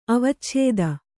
♪ avacchēda